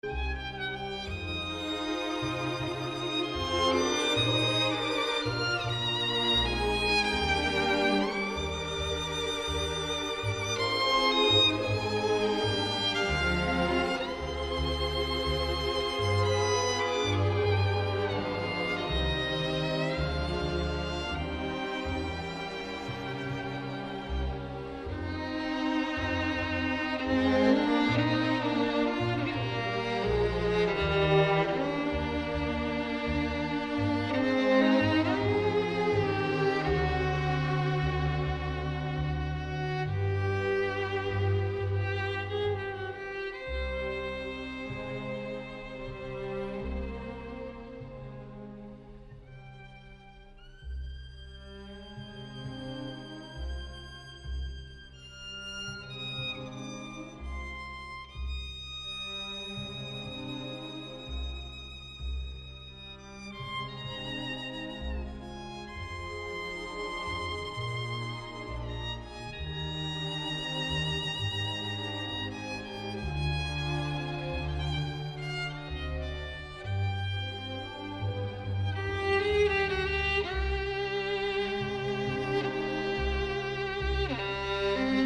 小提琴